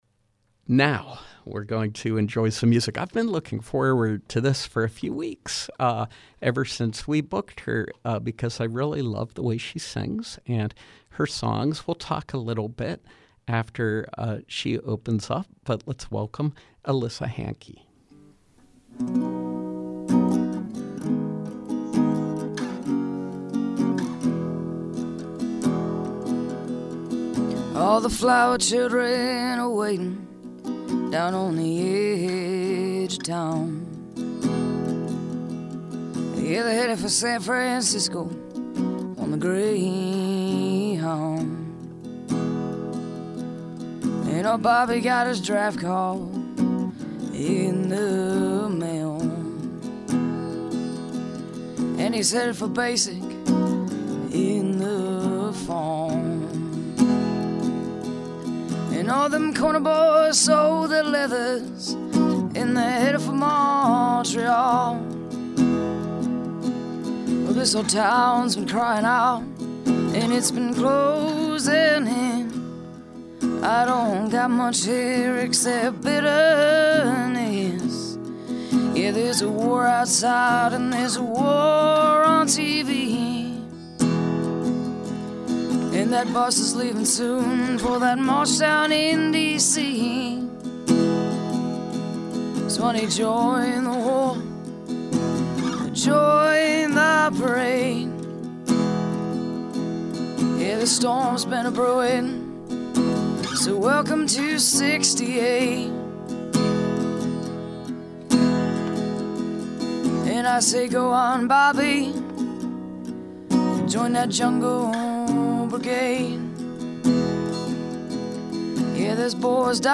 Live music with singer-songwriter